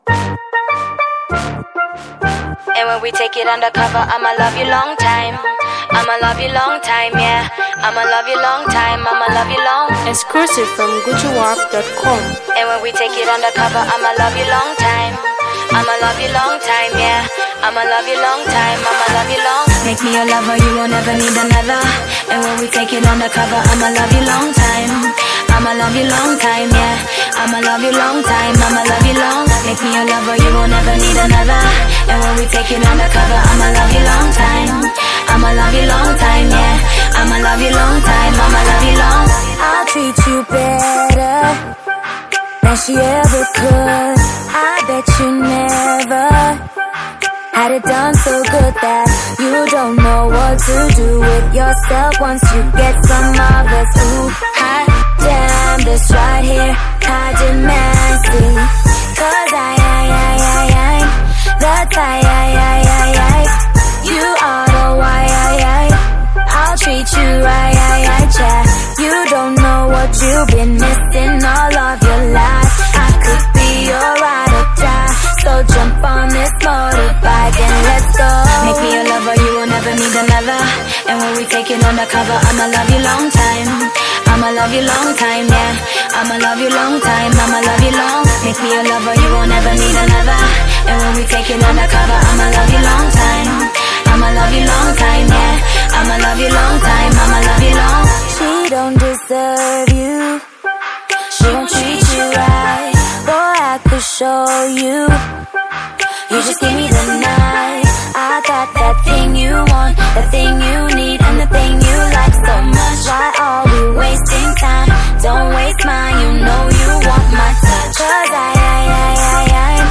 Musical Soulfulness